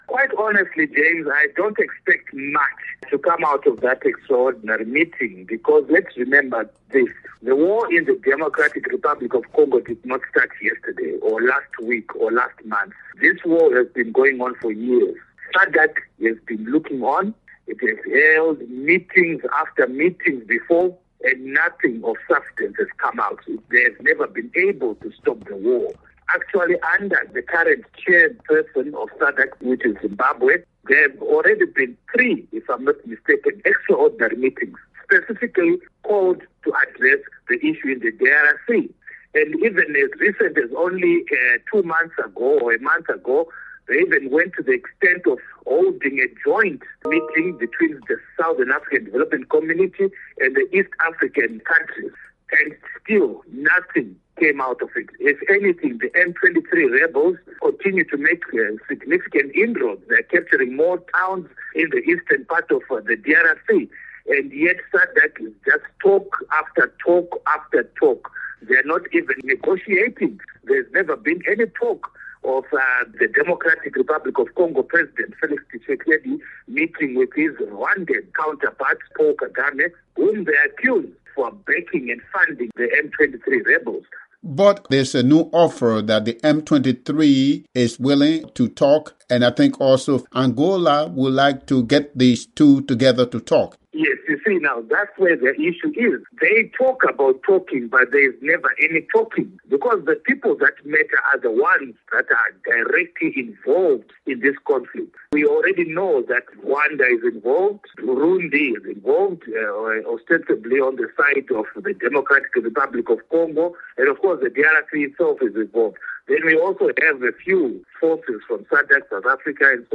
a Zimbabwe political analyst